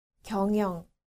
• 경영
• gyeongyeong